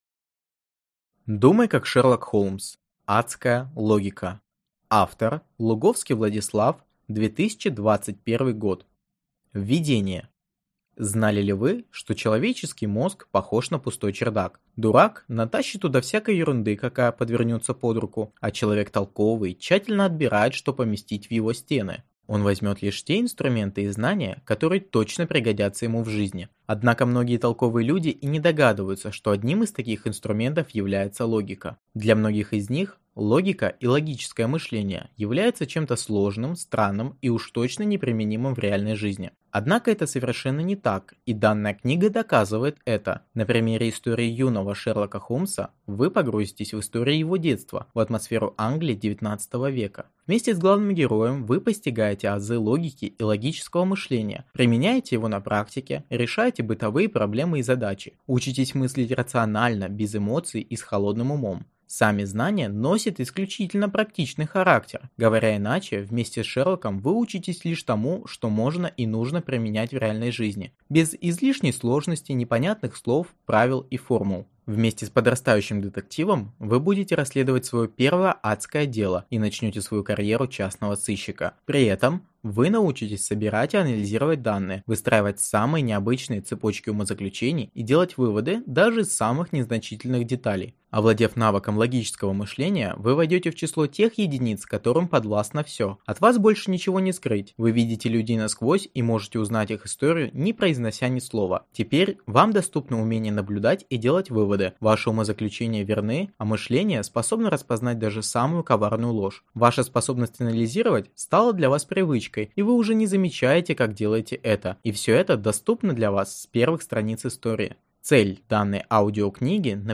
Аудиокнига Думай как Шерлок Холмс. Адская логика | Библиотека аудиокниг